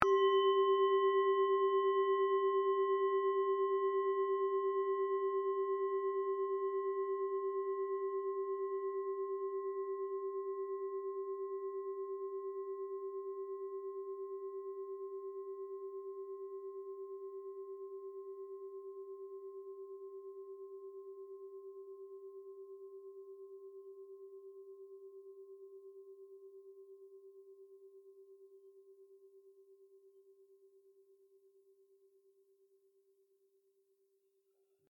cuenco6.ogg